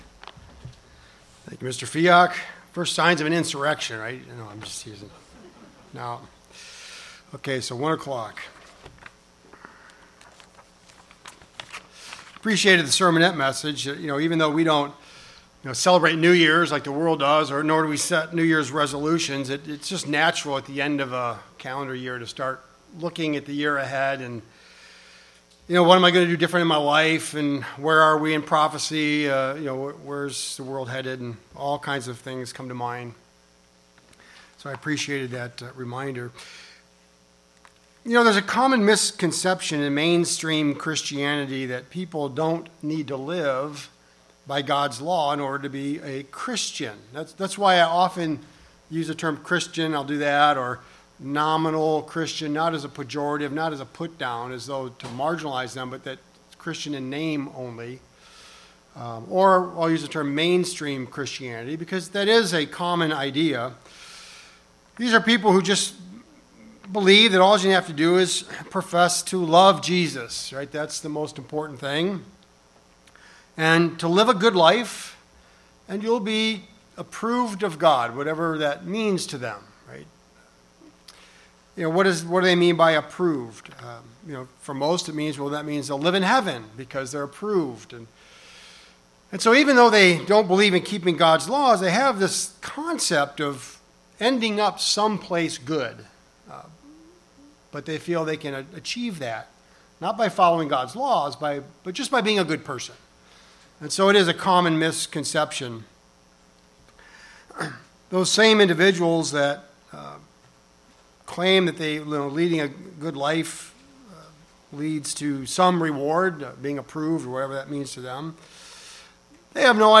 We all know the penalties for breaking Gods Law, todays sermon looks at the benefits of obeying Gods laws.